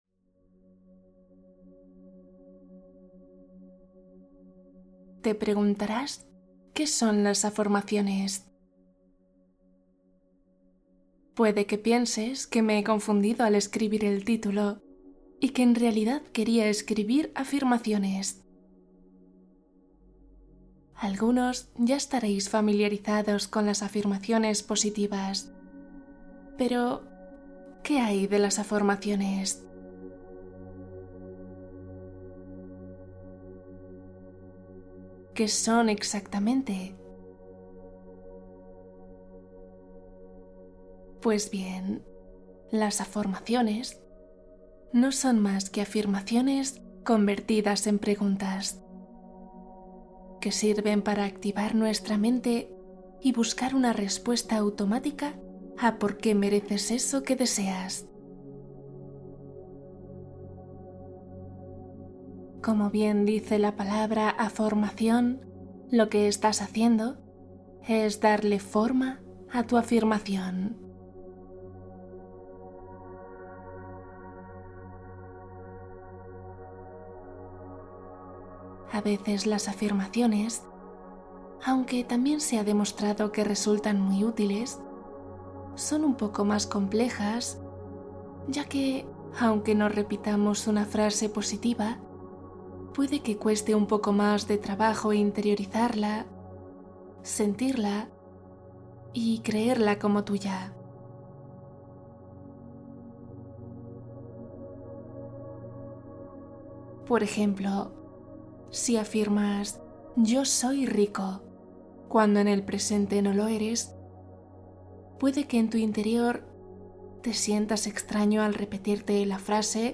Afirmaciones y meditación | Impulsa tus objetivos y eleva tu autoestima